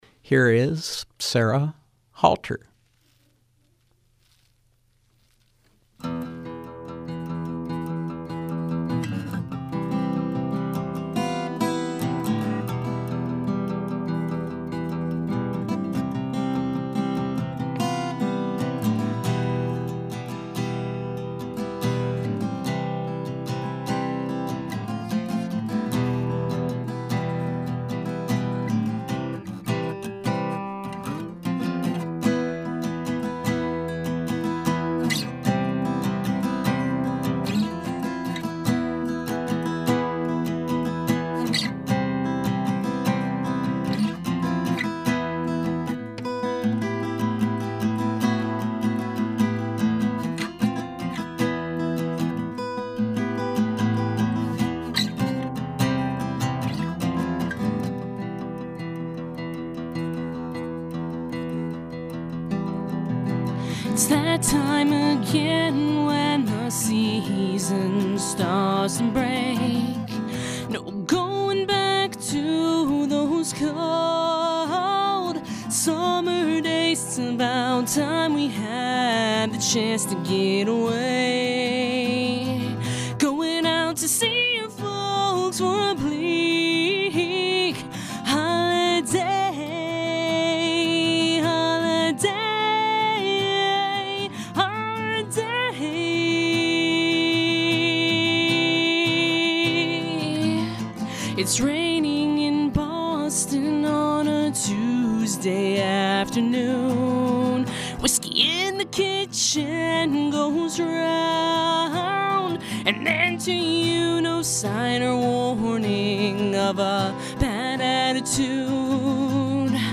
Live music with singer-songwriter